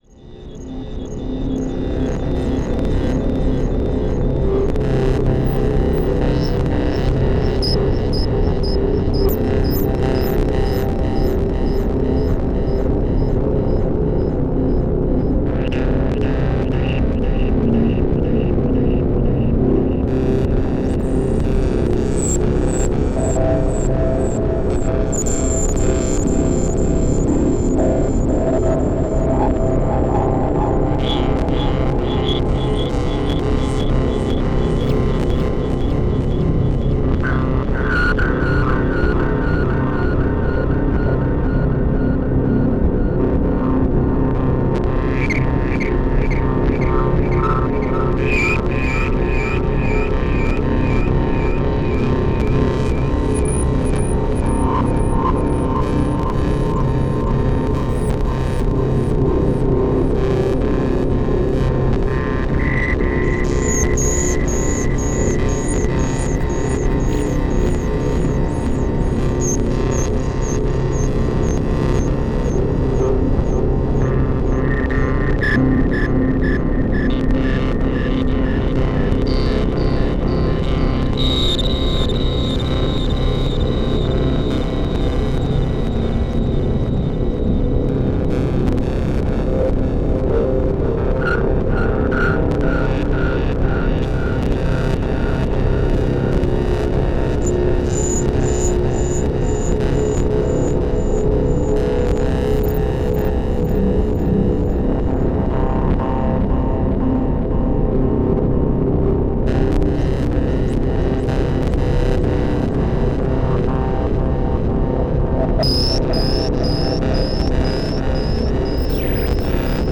live recorded experimental soundscapes
modular synthesizers... chilly ambient